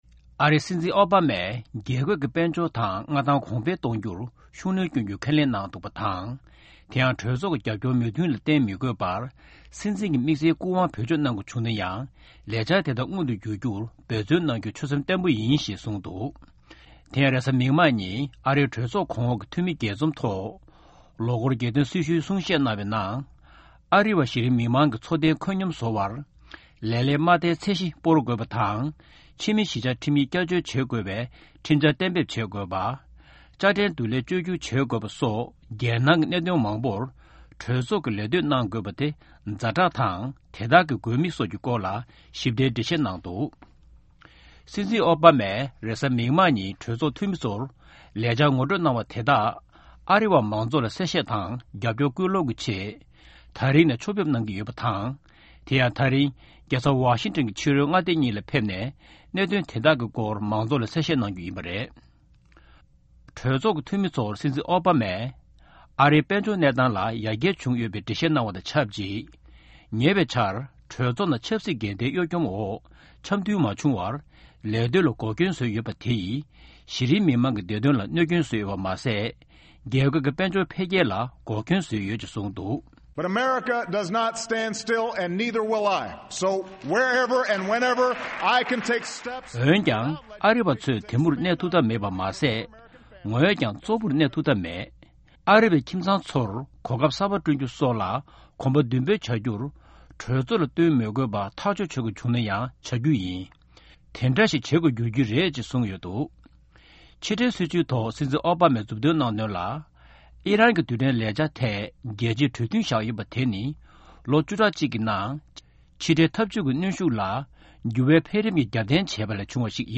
སྲིད་འཛིན་ཨོ་བྷ་མས་རྒྱལ་དོན་སྲི་ཞུའི་གསུང་བཤད་གནང་བ།